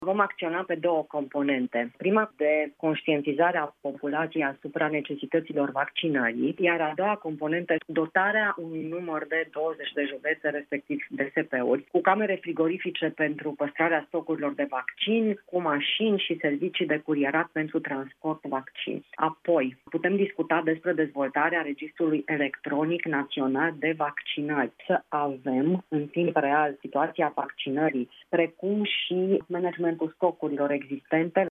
Ministrul Sănătăţii, Sorina Pintea, a declarat la Europa FM, că banii vor fi folosiţi în două direcţii principale: pentru campanii de conştientizare a populaţiei privind importanţa vaccinării şi pentru dotarea a 20 de județe identificate ca fiind prioritare cu camere frigorifice pentru păstrarea stocurilor de vaccin, cu mașini și servicii de curierat pentru transport vaccin.